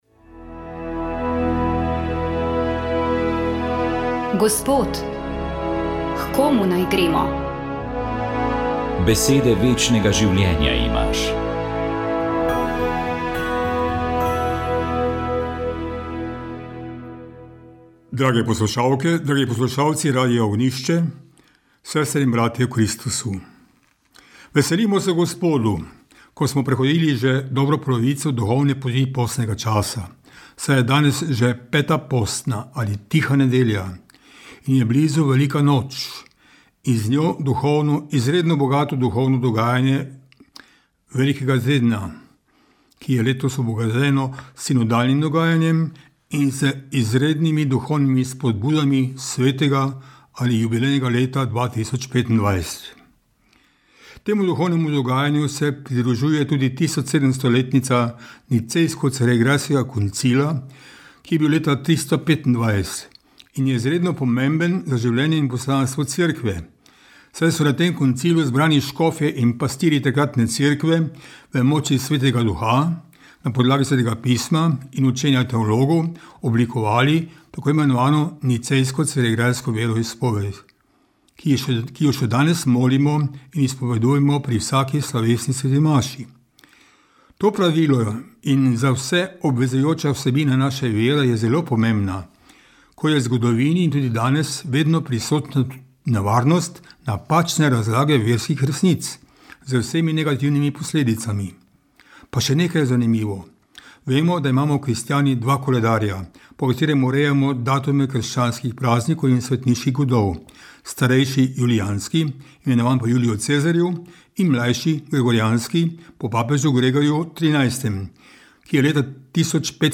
Tiha nedelja nas spominja, da je že zadnji čas in vabi, da bi ujeli še kakšen dan postnega časa. Da bi ostali bolj v tišini, v notranji tihoti, ki omogoča globje zaznavanje svojega notranjega doživljanja…je misel iz nagovora ki ga je pripravil nadškof Marjan Turnšek.